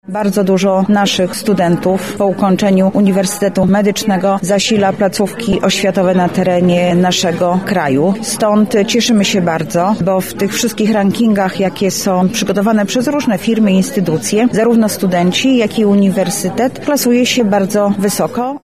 Dziś (21 listopada) w Filharmonii Lubelskiej odbyła się oficjalna uroczystość jubileuszowa największej uczelni medycznej w naszym regionie.
Anna Augustyniak– podkreśla Anna Augustyniak – Zastępca Prezydenta ds. Społecznych.